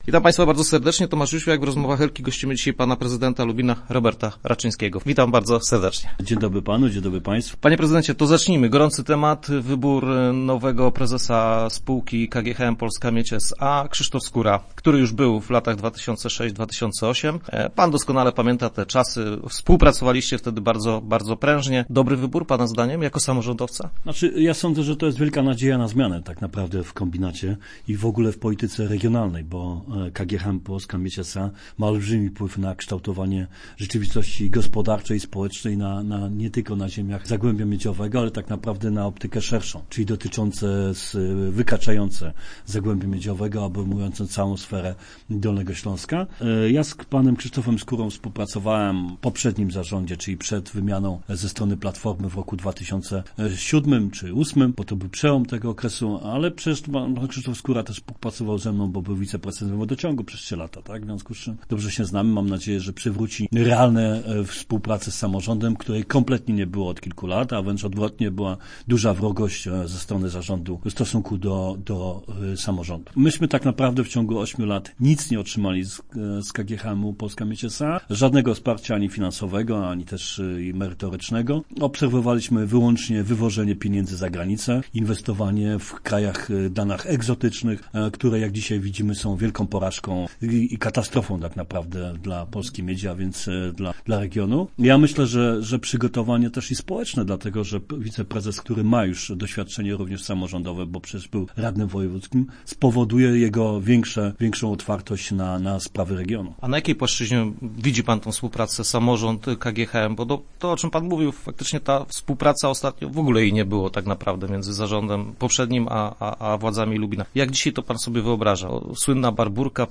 Czy wnioski będzie można wypełnić elektronicznie czy jednak będzie trzeba swoje odstać? Gościem poniedziałkowych Rozmów był prezydent Lubina, Robert Raczyński.